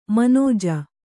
♪ manōja